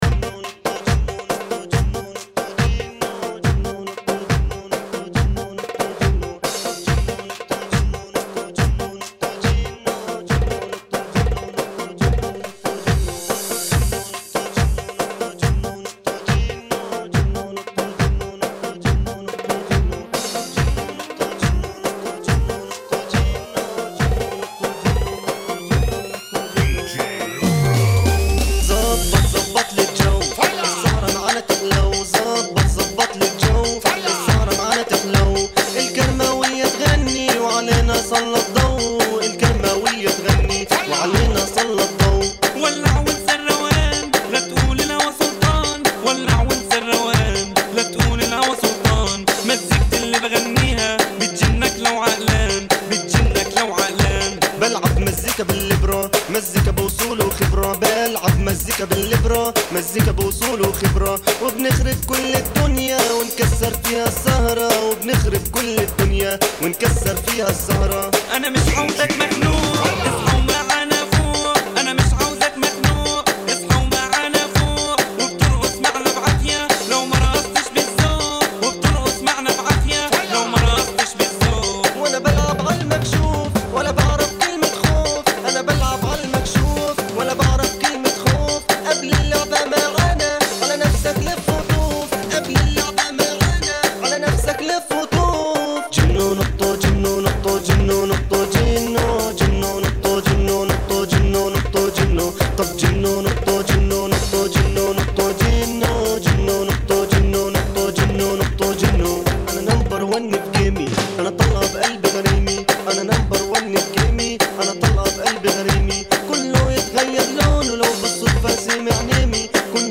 70 Bpm
شعبي مصري